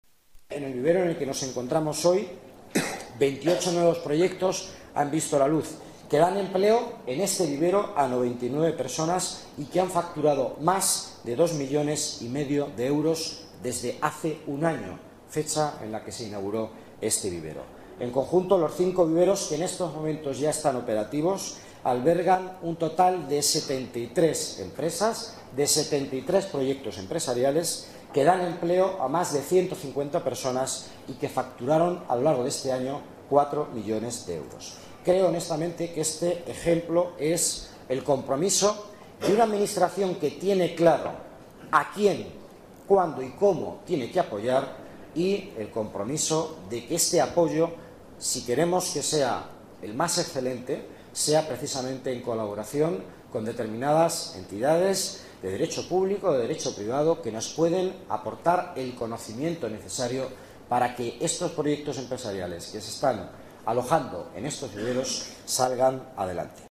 Nueva ventana:Declaraciones del delegado de Economía, Miguel Ángel Villanueva: Jóvenes Empresarios